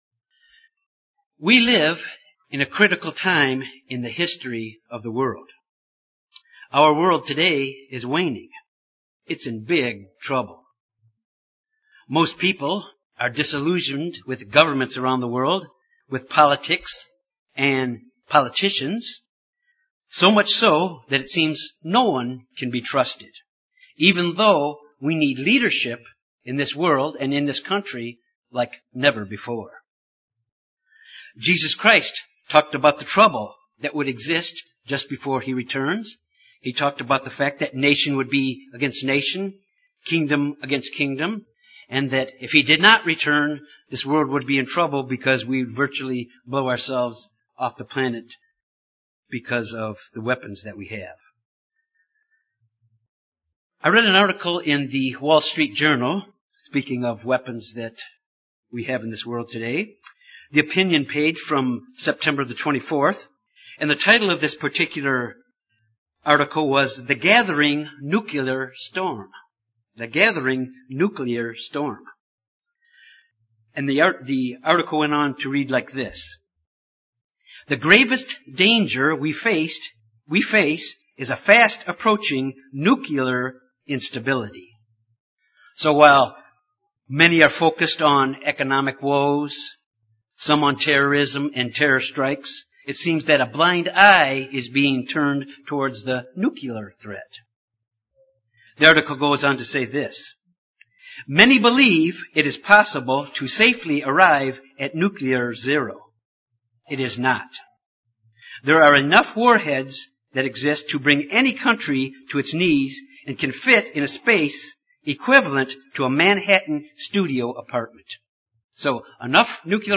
Sermons
Given in Jonesboro, AR Little Rock, AR